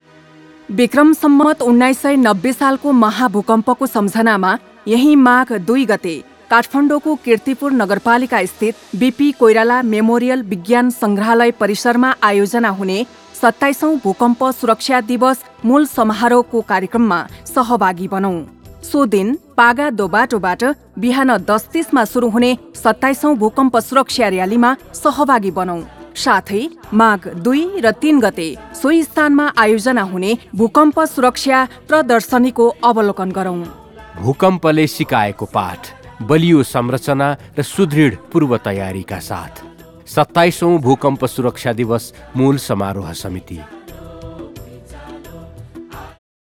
ESD Audio/Video PSA
Earthquake PSA 1.wav